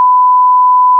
sin1000Hz.wav